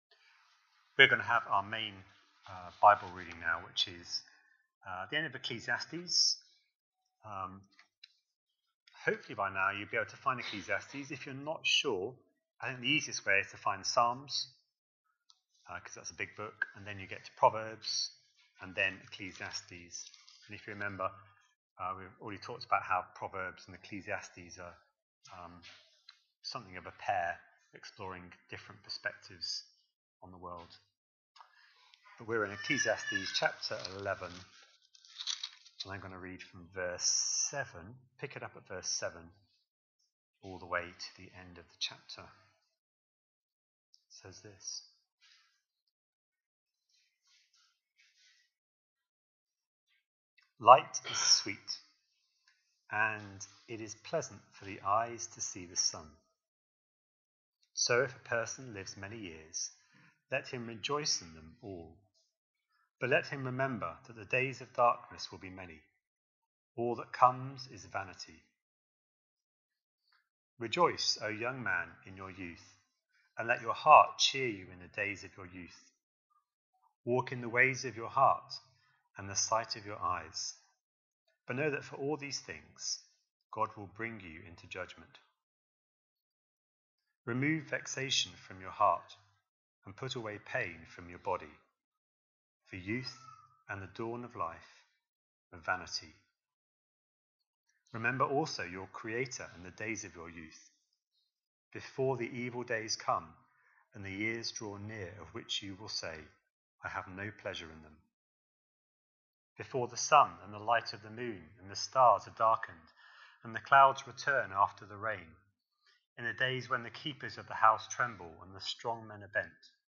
A sermon preached on 25th May, 2025, as part of our Ecclesiastes series.